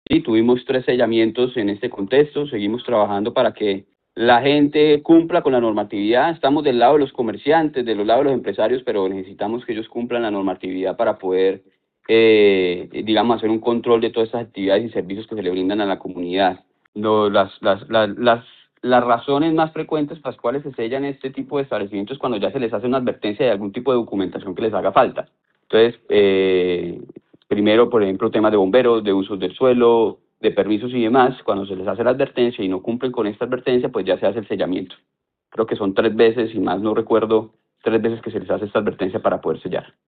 Alfonso Pinto, secretario del interior de Bucaramanga